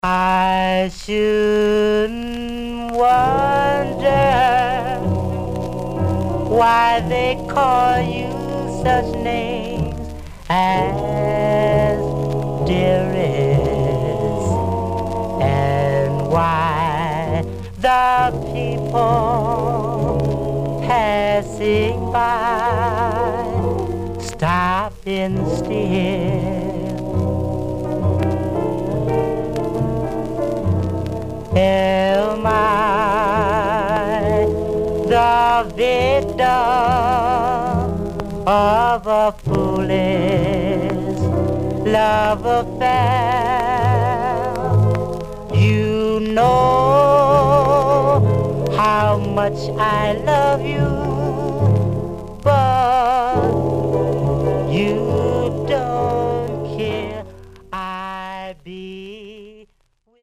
Stereo/mono Mono
Vocal Group